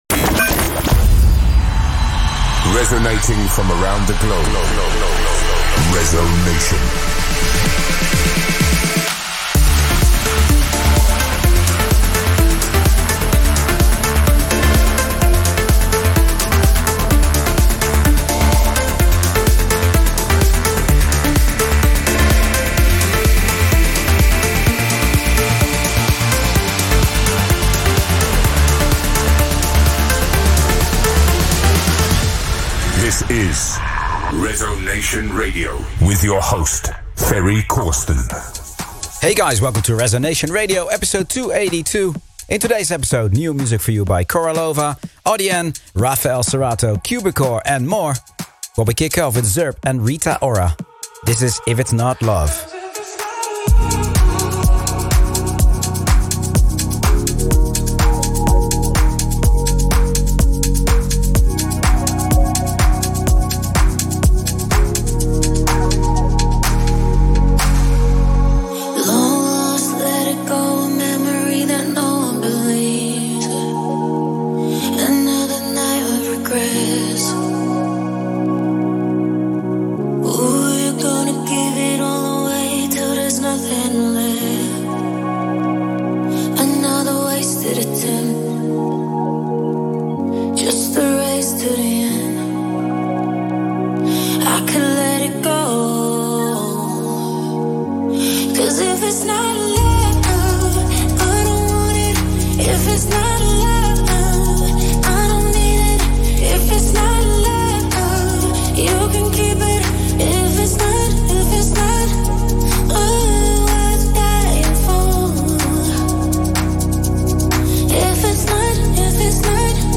music DJ Mix in MP3 format
Genre: Trance